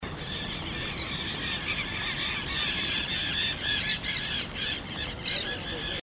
Ñanday (Aratinga nenday)
Nombre en inglés: Nanday Parakeet
Fase de la vida: Adulto
Localidad o área protegida: Reserva Ecológica Costanera Sur (RECS)
Condición: Silvestre
Certeza: Fotografiada, Vocalización Grabada